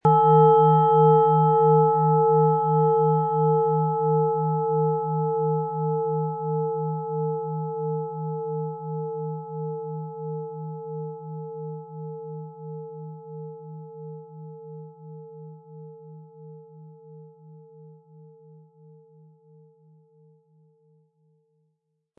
Planetenschale® Gelassen und Beruhigt sein & Ausgeglichen fühlen mit Thetawellen & Wasserstoffgamma, Ø 15,3 cm, 320-400 Gramm inkl. Klöppel
Mit viel Liebe und Sorgfalt in Handarbeit erstellte Klangschale.
• Tiefster Ton: Wasserstoffgamma
Um den Original-Klang genau dieser Schale zu hören, lassen Sie bitte den hinterlegten Sound abspielen.
PlanetentöneThetawelle & Wasserstoffgamma
MaterialBronze